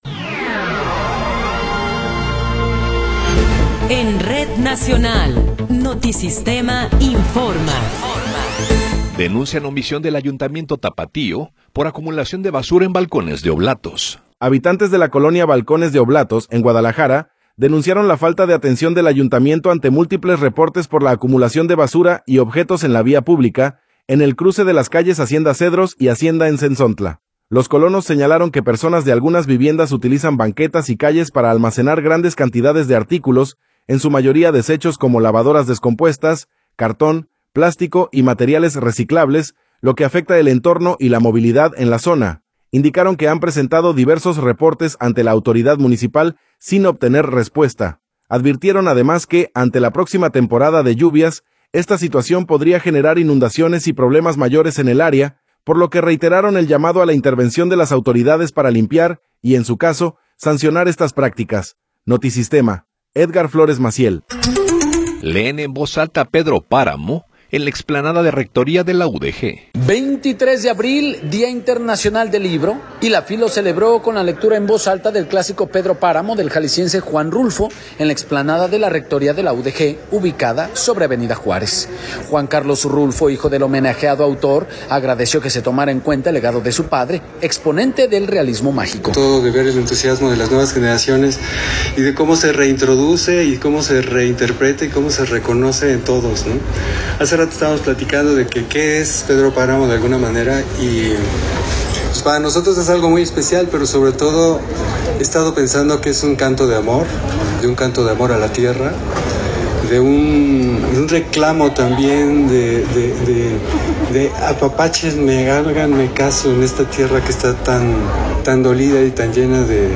Noticiero 16 hrs. – 23 de Abril de 2026